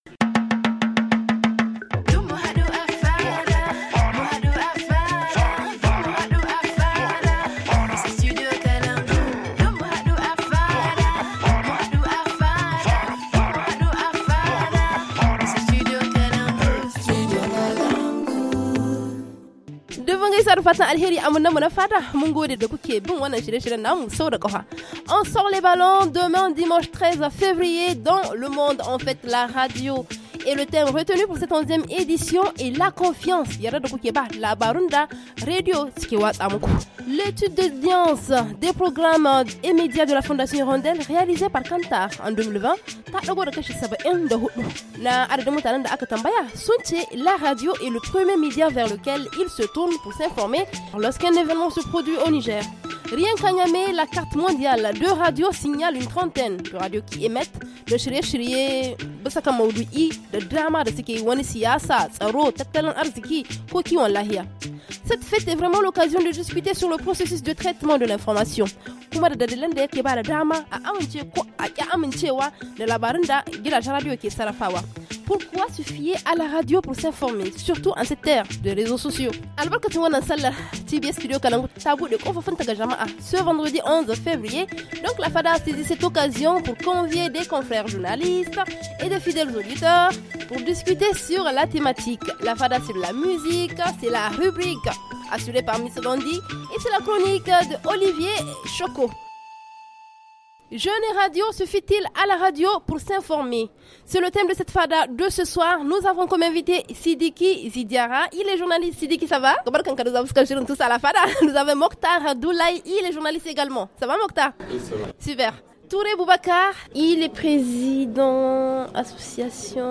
Nous avons profité de la journée portes ouvertes organisée par le studio kalangou le vendredi 11 février, pour convier des confrères journalistes et des fidèles auditeurs pour comprendre pourquoi se fier à la radio pour s’informer surtout en cette ére des réseaux sociaux ?